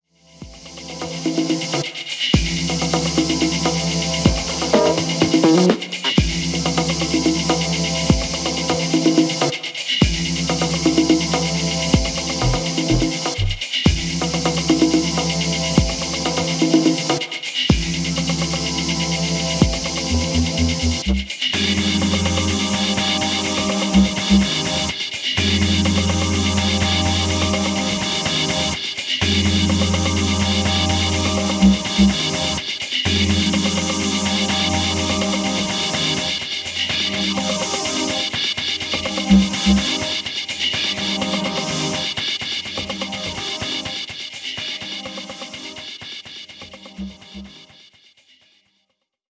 ディープなニューエイジ/アンビエント的アプローチの楽曲を展開